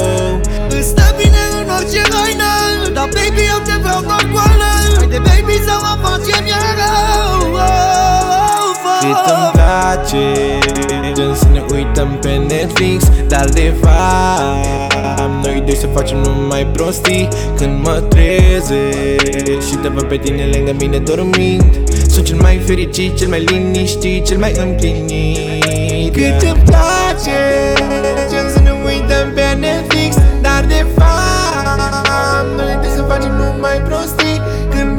Жанр: Латиноамериканская музыка / Рэп и хип-хоп
# Latin Rap